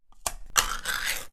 tincanopen.ogg